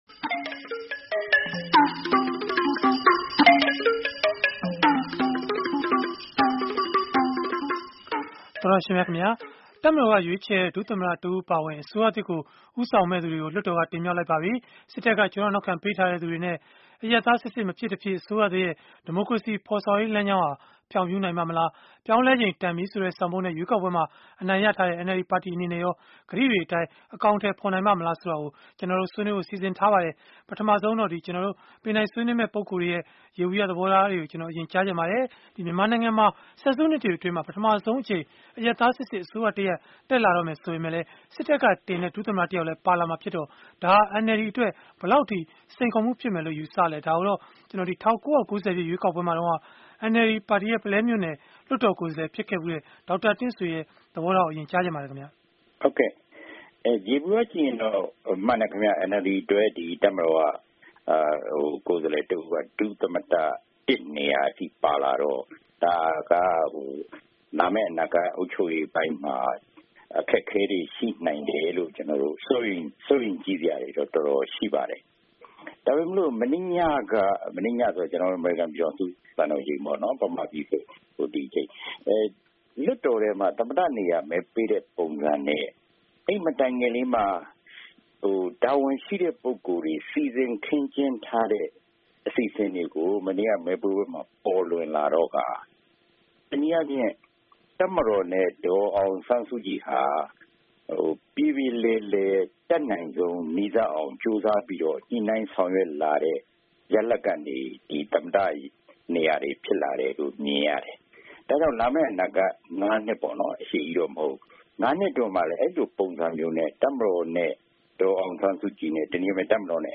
ဆယ်စုနှစ်တွေအတွင်း ပထမဆုံးအကြိမ် အရပ်သား အစိုးရတရပ် တက်လာတော့မှာ ဖြစ်ပေမဲ့ စစ်တပ်က ကျောထောက်နောက်ခံ ပေးထားသူတွေ ပါလာမယ့် NLD အစိုးရသစ်ရဲ့ ဒီမိုကရေစီ ဖော်ဆောင်ရေး လမ်းကြောင်းဟာ ဖြောင့်ဖြူးနိုင်ပါ့မလား ဆိုတာကို အင်္ဂါနေ့ည တိုက်ရိုက်လေလှိုင်း အစီအစဉ်မှာ ဆွေးနွေးထားပါတယ်။